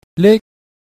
3. Consonne initiale + voyelle brève + consonne finale en k, p, t = ton haut